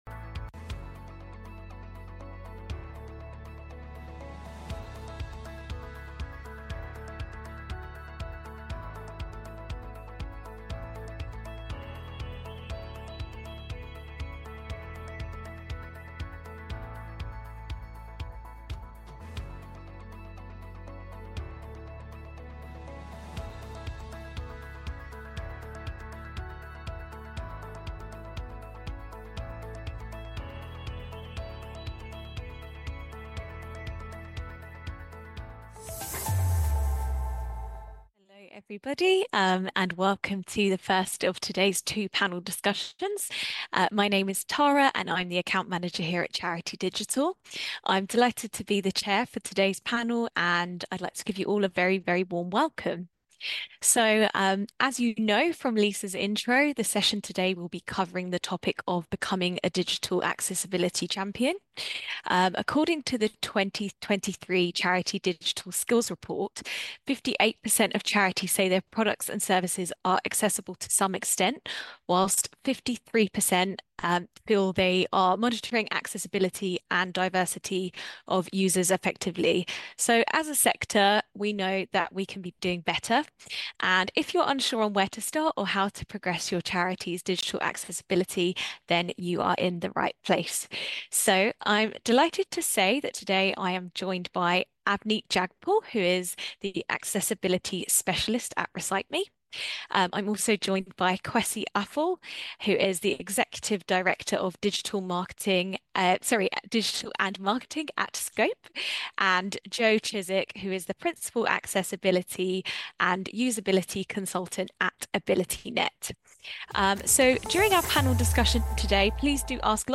In this podcast, recorded at Charity Digital's Digital Inclusion Summit in June 2024, we are joined by a panel of experts to share advice and learnings to help the charity sector improve its digital accessibility.